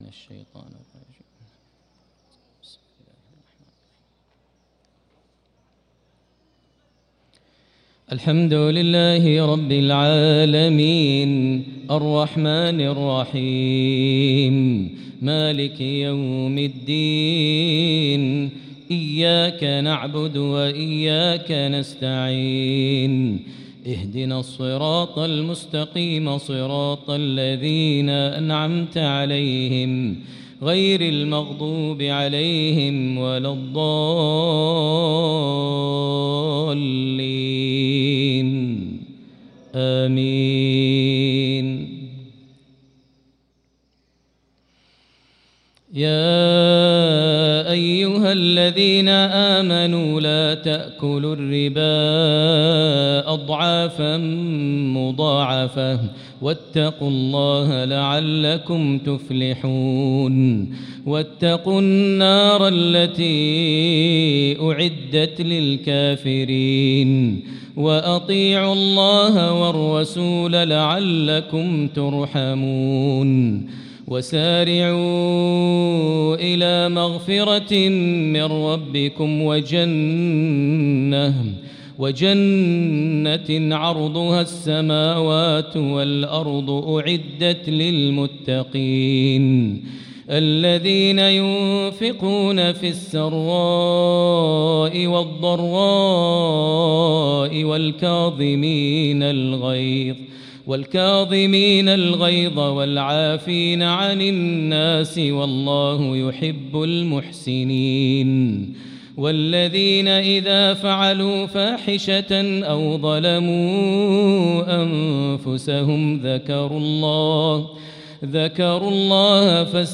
صلاة العشاء للقارئ ماهر المعيقلي 5 شعبان 1445 هـ
تِلَاوَات الْحَرَمَيْن .